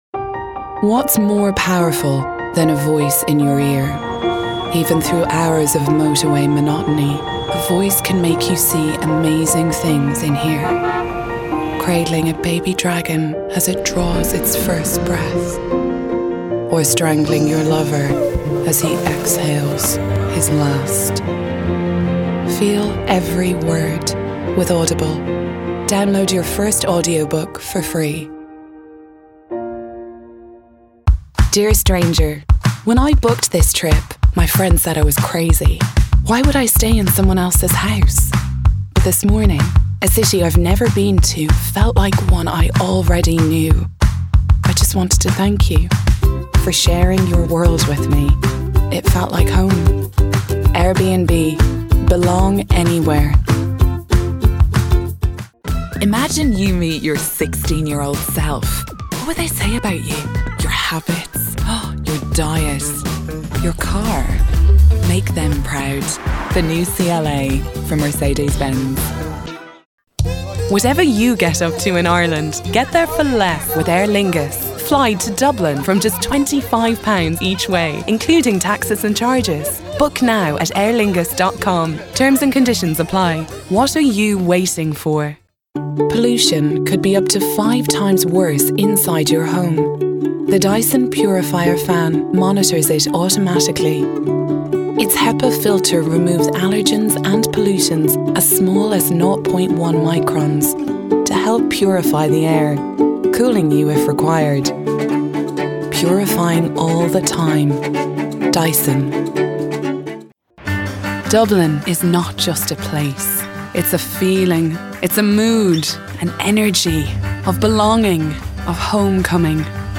Female
Rode Mic (NT1A) , Focusrite Scarlett 2i2 ,
20s/30s, 30s/40s
Irish Neutral